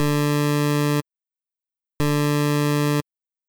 Sound of 80/20 PMW D-note: